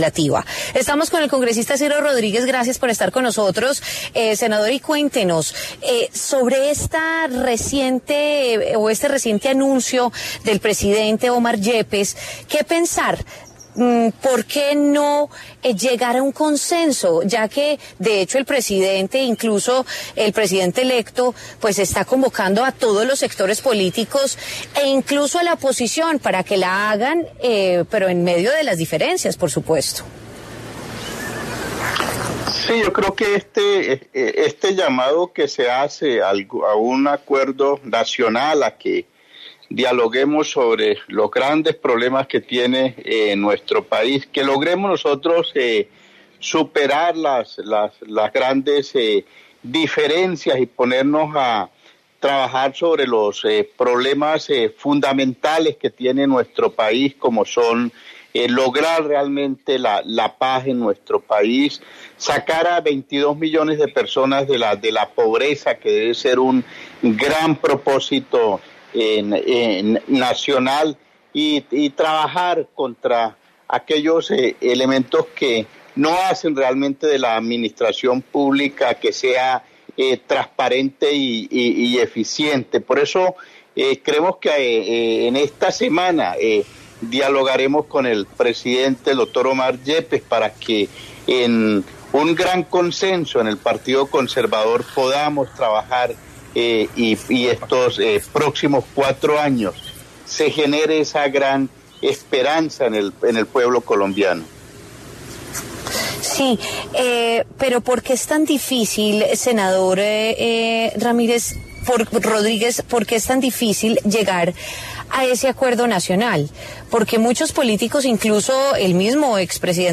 Omar Yepes, presidente del Partido Conservador y Ciro Rodríguez, miembro de la Cámara de Representantes hablan en W Fin de Semana sobre dicho anuncio.